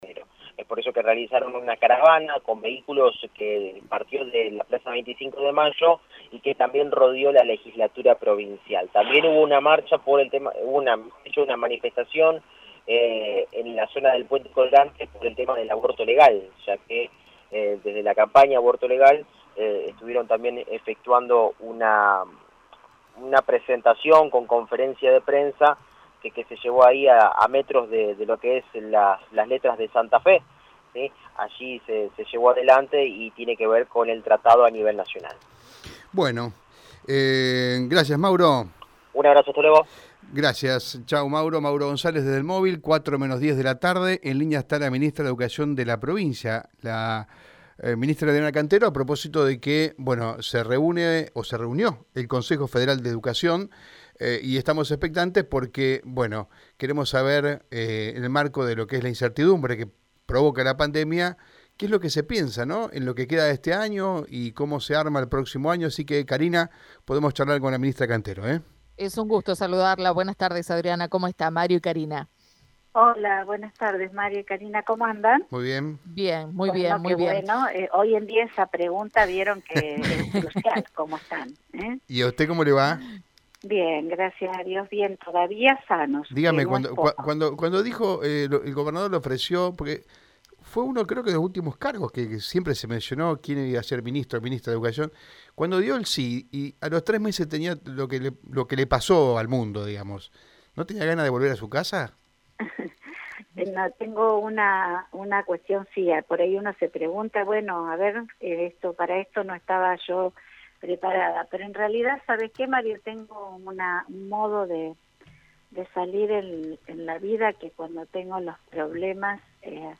En dialogo con Radio EME, la ministra de Educación de la provincia de Santa Fe, Adriana Cantero, habló sobre la reunión del Consejo Federal de Educación, donde se acordó que cada distrito definirá el calendario escolar 2021.